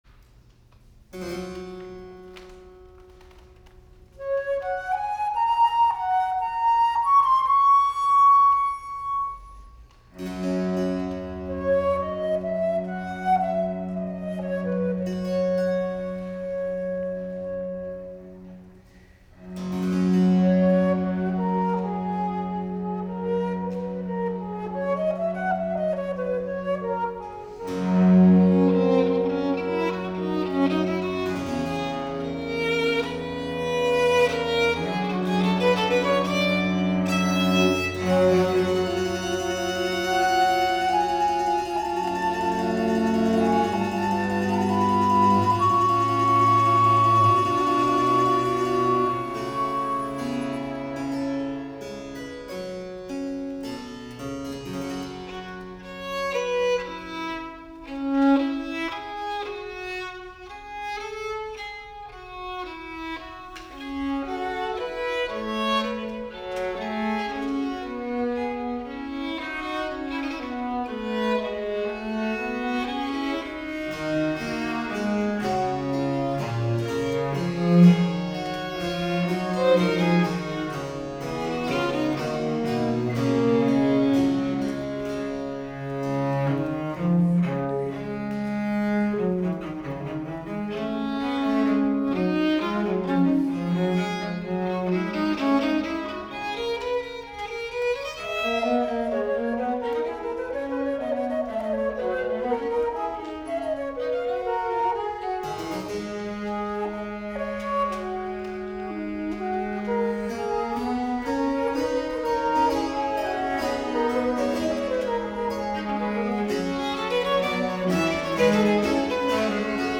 a period instrument ensemble.